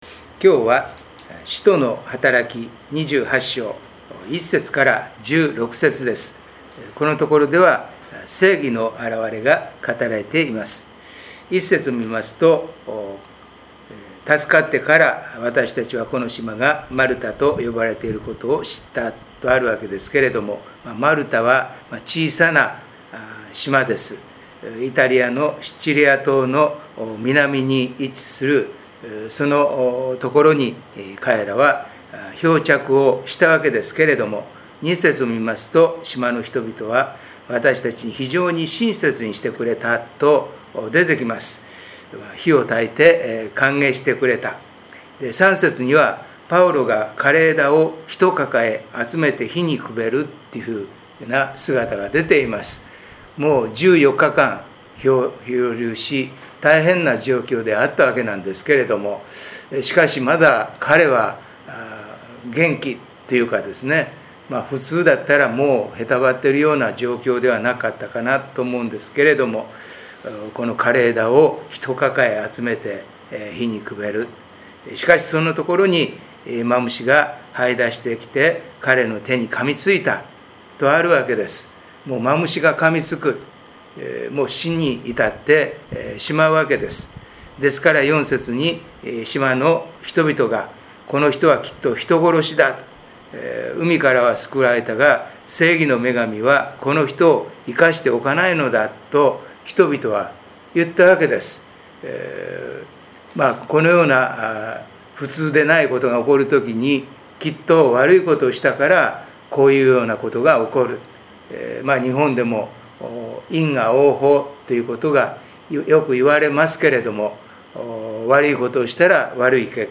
聖書研究祈祷会音声